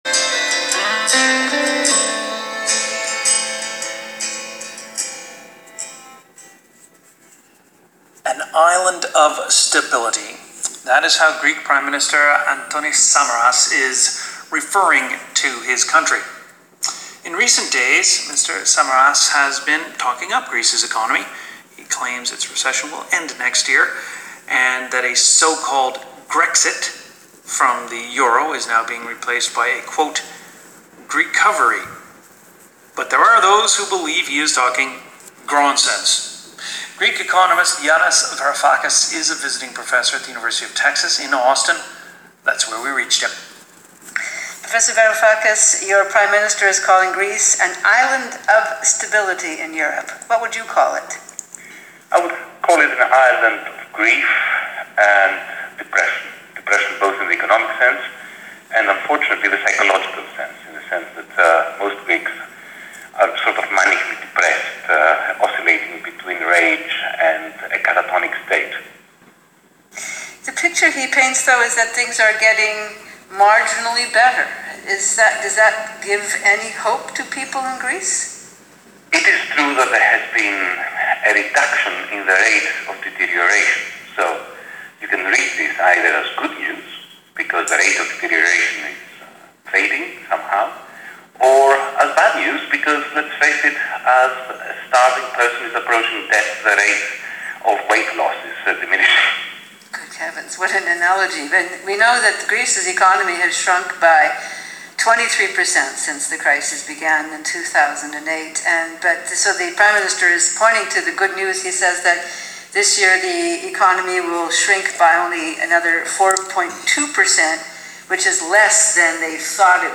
Greece an "island of stability"? On CBC radio